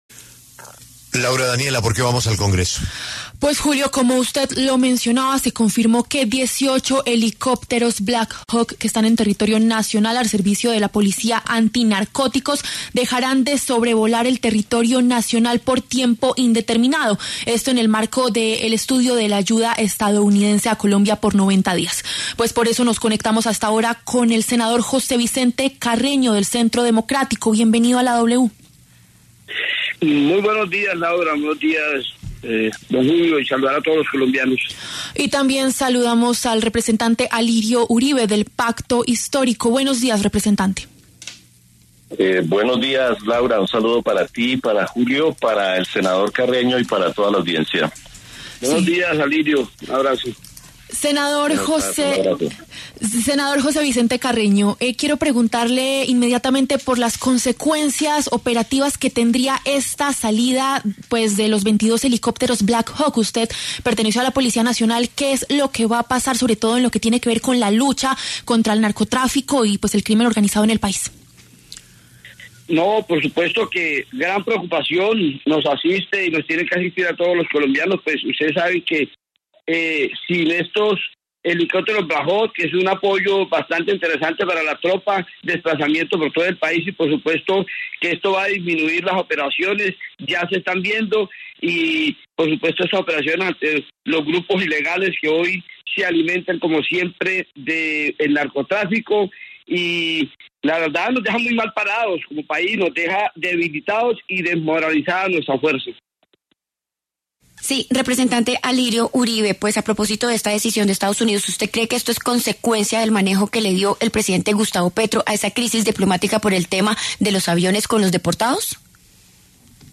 El senador José Vicente Carreño, del Centro Democrático, y el representante Alirio Uribe, del Pacto Histórico, pasaron por los micrófonos de La W.